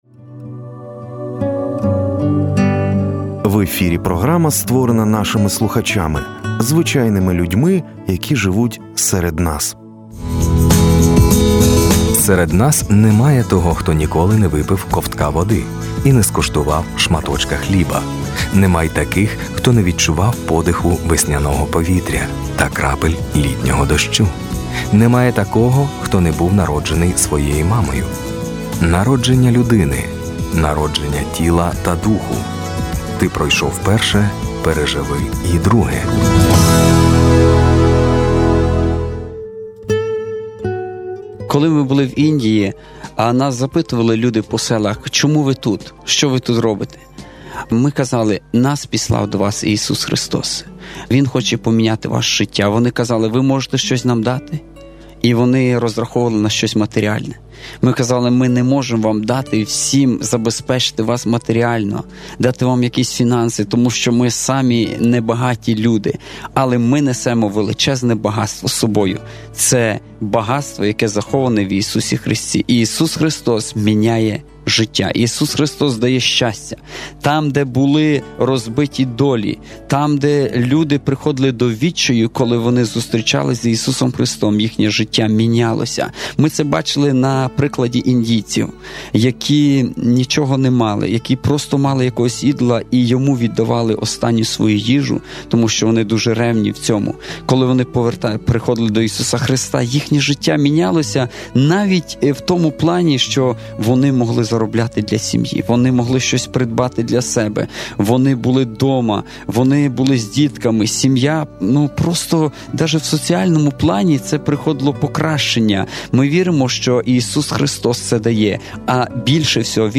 Ісус змінює життя: свідчення з Індії | Архів Світлого Радіо
Місіонер ділиться своїми спостереженнями, як прийняття Ісуса Христа кардинально змінює долі, наповнюючи життя новим сенсом та надією.